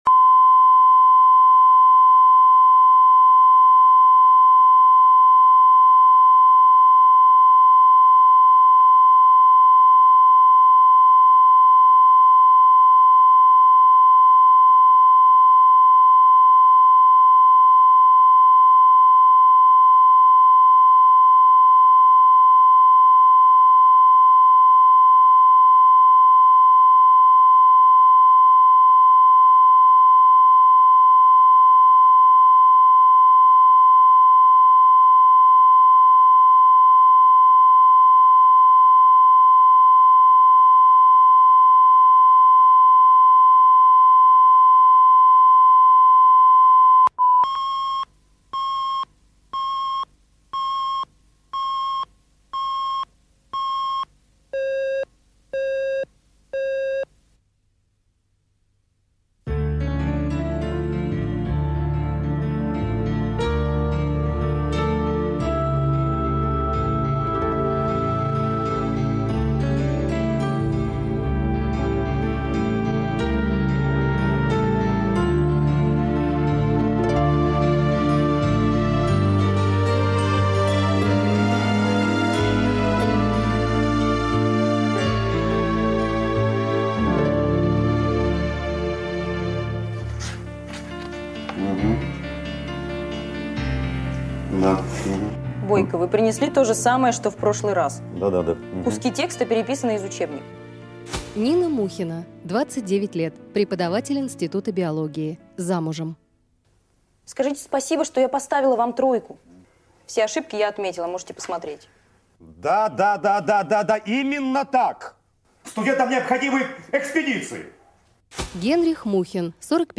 Аудиокнига Три желания | Библиотека аудиокниг